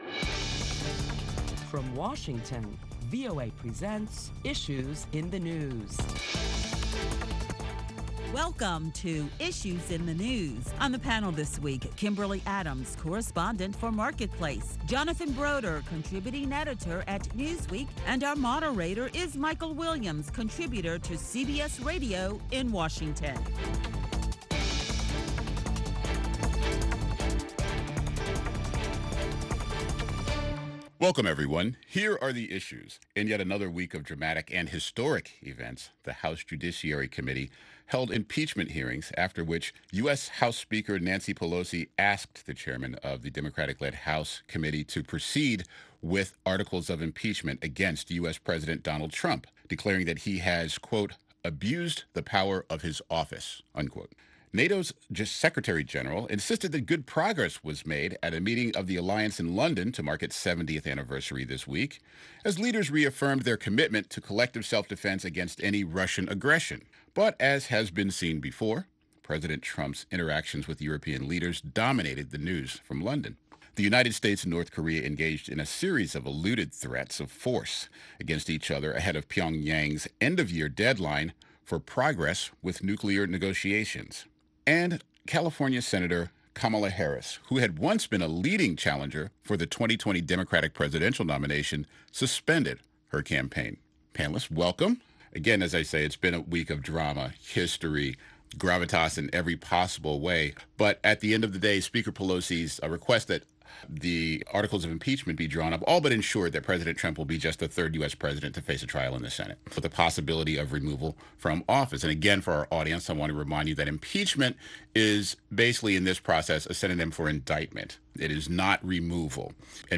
Listen to a panel of prominent Washington journalists as they deliberate the latest top stories that include the Democrat-led House Judiciary Committee proceeds with articles of impeachment against President Donald Trump.